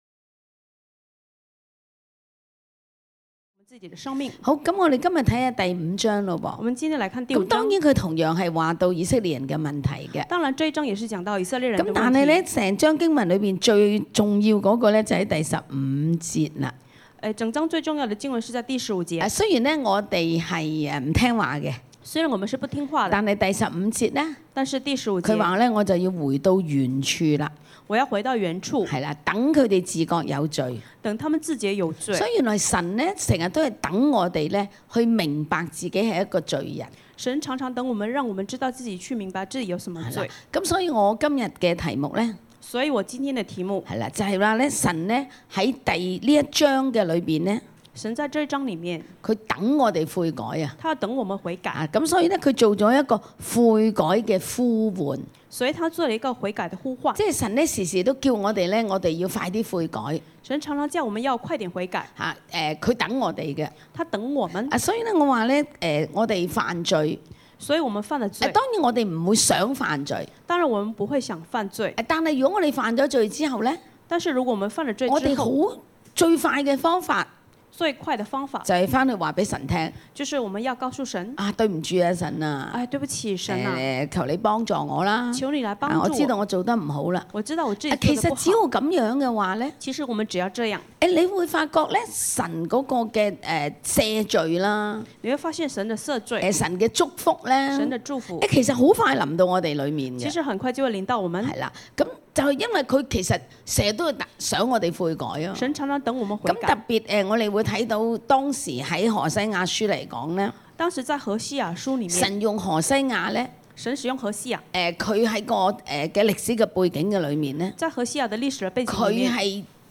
有弟兄姐妹出來分享神跟他們說的話：*神提醒我太獨立自我，要更多與人連接，因爲神是為關係而死的。